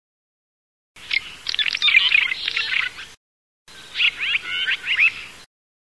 Jaskółka rudawa - Hirundo daurica
głosy